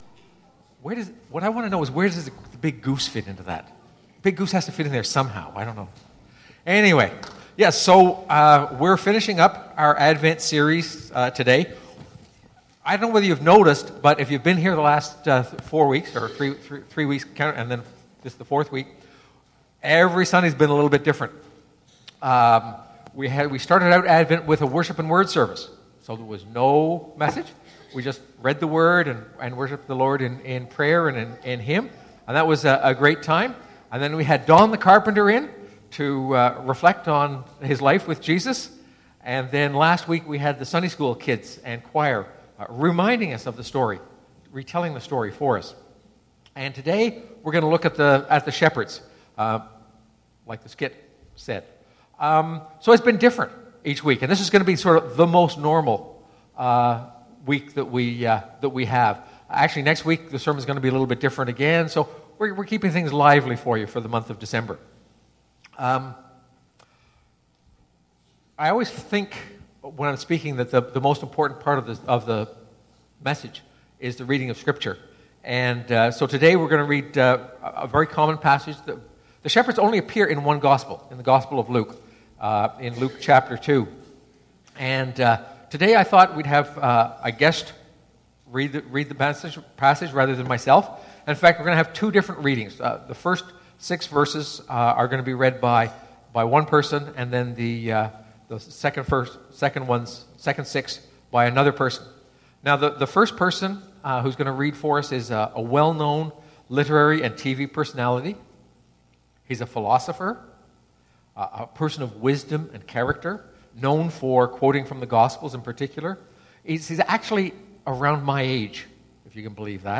This sermon is based on Luke 2:8-20.